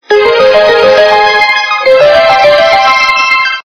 звуки для СМС